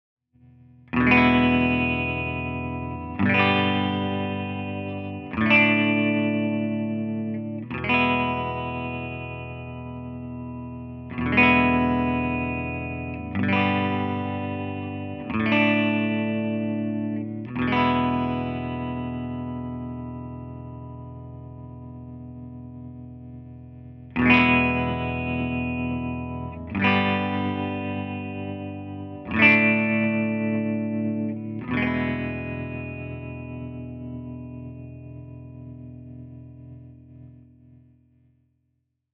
My latest guitar amp project is a stereo amp with vibrato and reverb.
tremolostereo1_r1_session.flac